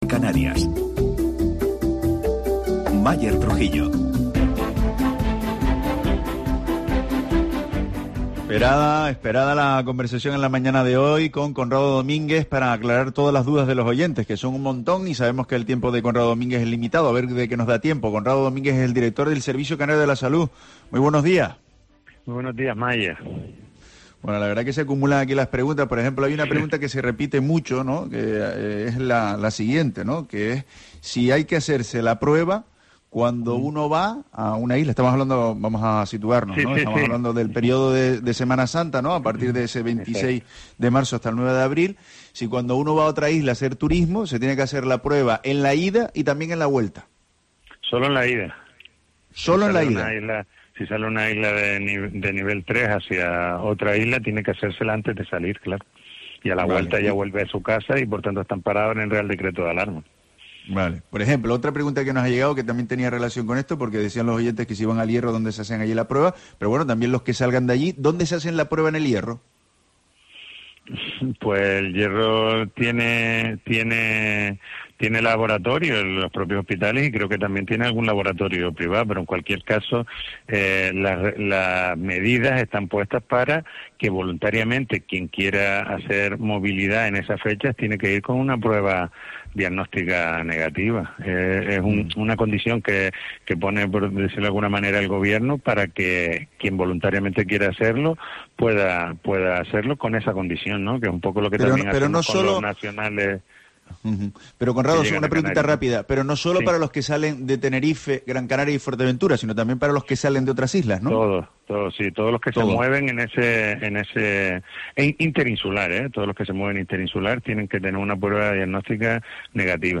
Entrevista Conrado Domínguez, director del Servicio Canario de la Salud